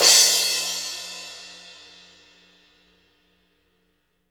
Index of /90_sSampleCDs/Roland L-CD701/CYM_Crashes 1/CYM_Crash menu